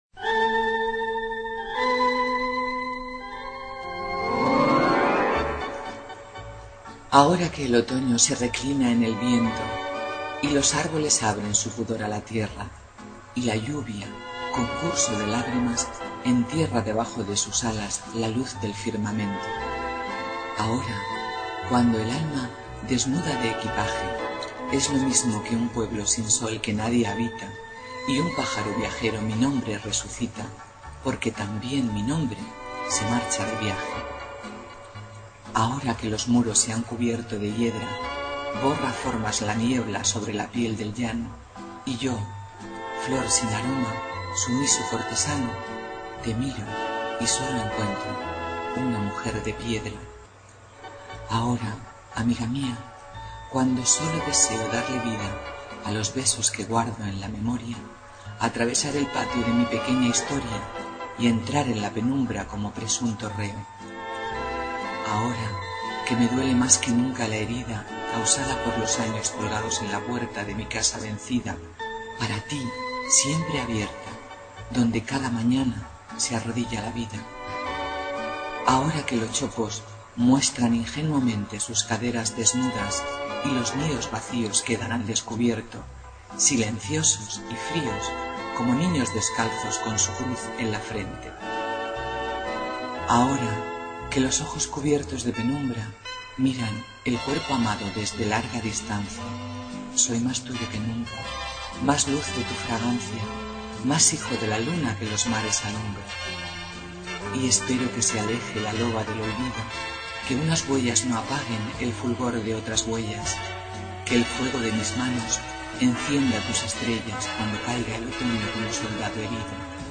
Inicio Multimedia Audiopoemas Latidos de otoño.
(Recitado por